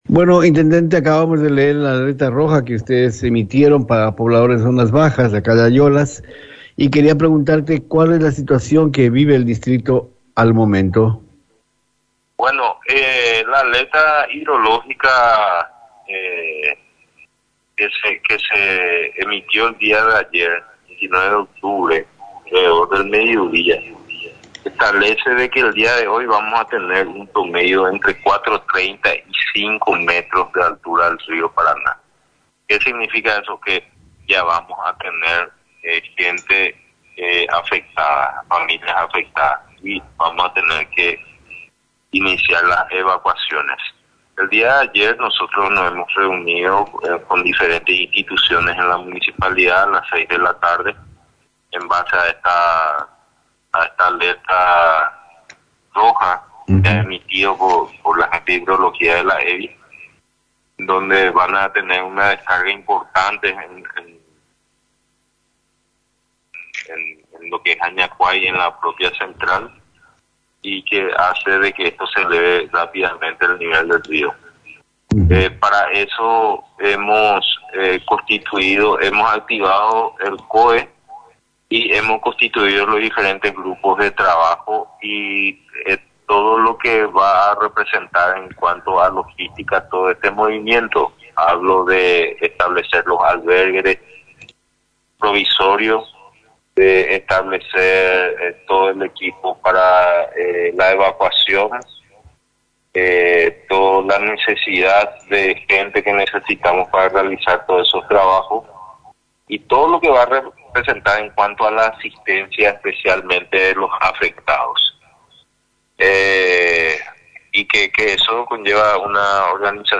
El intendente de la Municipalidad de Ayolas, Abg. Carlos Duarte en comunicación con MISION FM, explicó la reactivación del COE teniendo en cuenta los últimos eventos hidrológicos y meteorológicos, nuevamente sale a luz la necesidad urgente de la construcción de la defensa costera, pero en este momento la prioridad es la asistencia a las familias afectadas,   explicó además, que los presidentes de barrios ya están llevando a cabo un relevamiento del grado de afectación en cada sector, y ya se maneja que la riada llegará a la zona céntrica del Barrio San Antonio y Antequera.